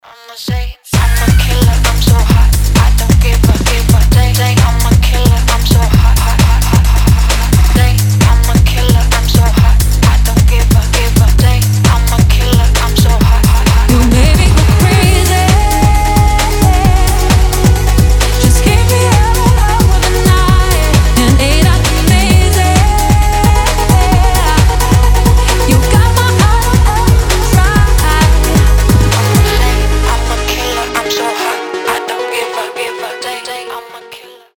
• Качество: 320, Stereo
атмосферные
Trap
басы
progressive house
цепляющие